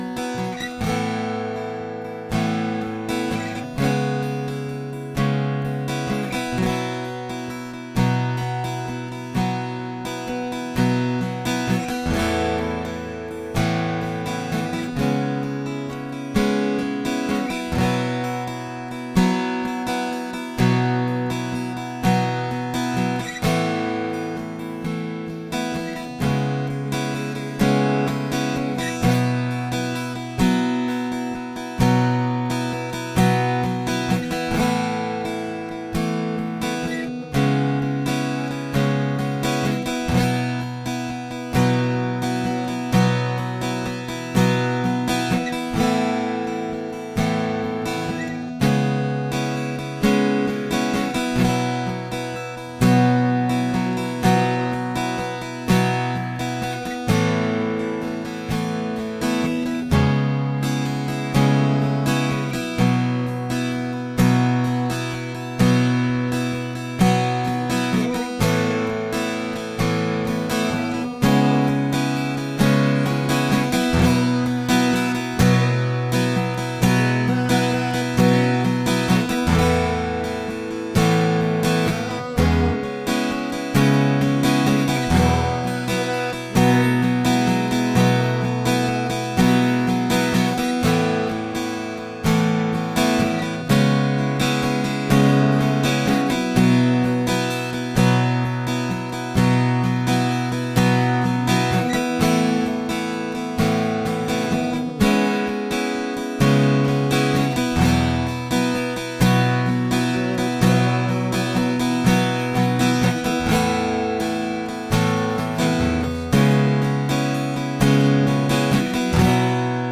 SERMON DESCRIPTION God led Jacob from fear to faith and fulfillment, guiding him safely to Haran where His promises began to unfold through meeting Rachel.